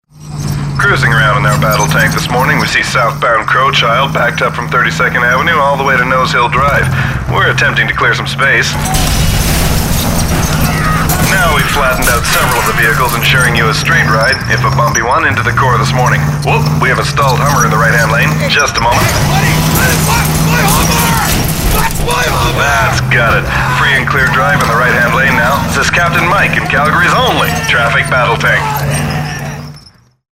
BattleTank.mp3